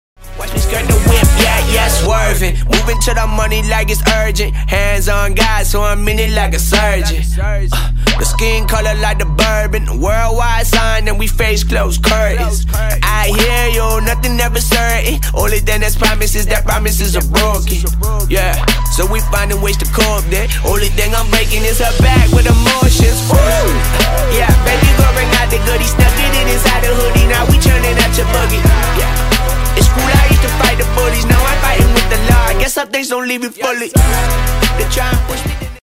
Hip-Hop Style
pe muzică antrenantă